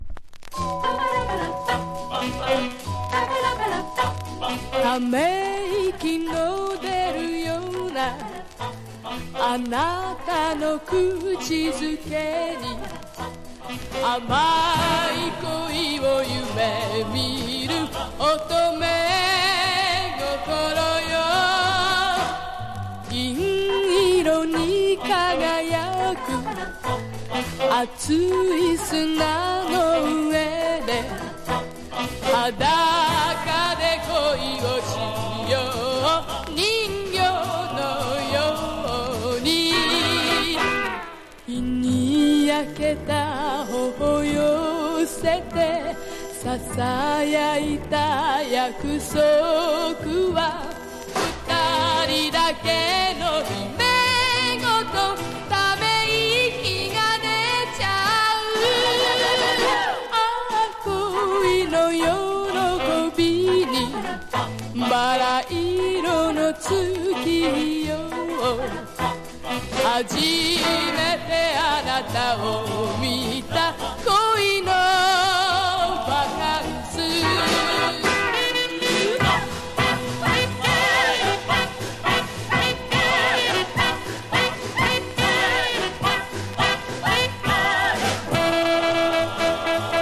彼女の圧倒的な歌唱力を確認！！
POP